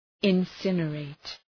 Προφορά
{ın’sınə,reıt}